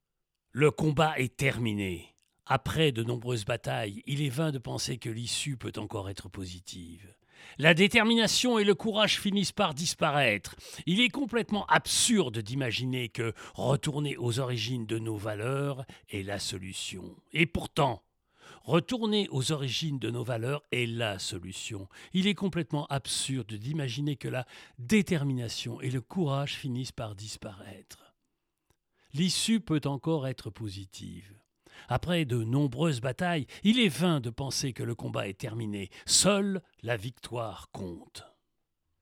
Voix off
AUDIO GUIDE PLACE DES ARCADES
50 - 100 ans - Baryton-basse